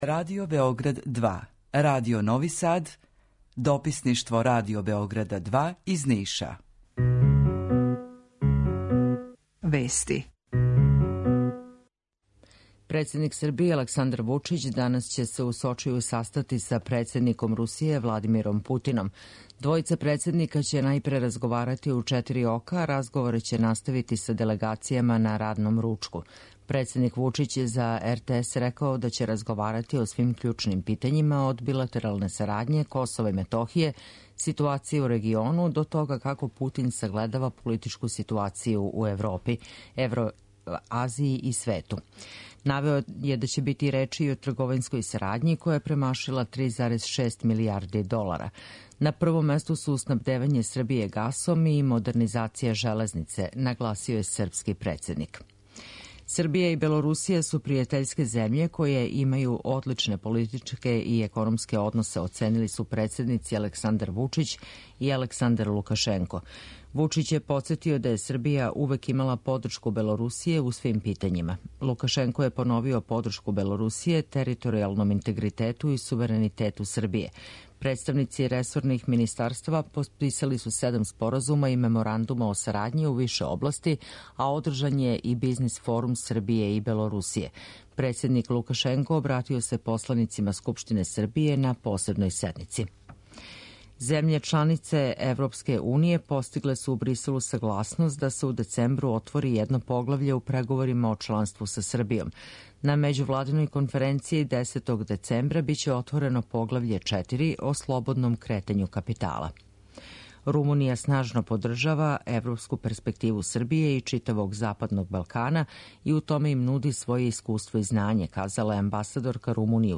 Укључење Радио Грачанице